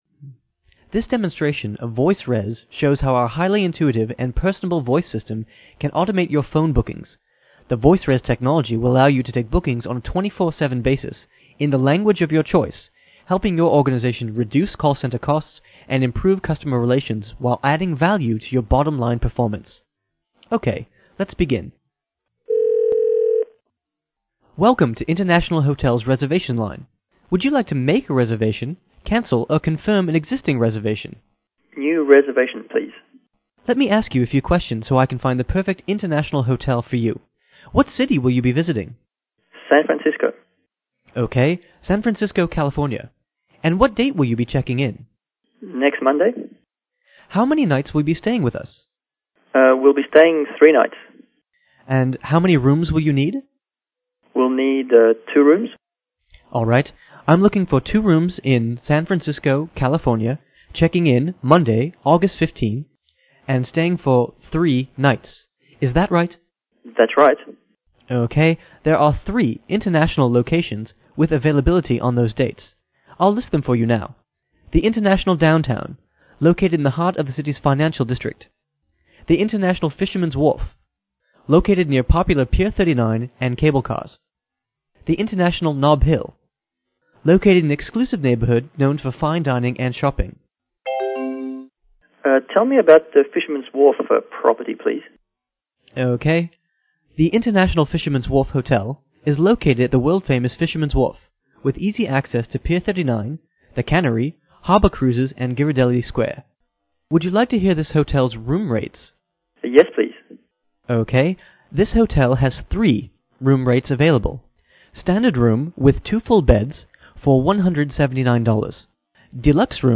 Voxify provides next generation automated voice systems through their sophisticated voice application platform. The Voxify platform allows clients to cost effectively implement robust natural language voice systems for mission critical customer service and other enterprise applications.
hotel_intlaccent_final.mp3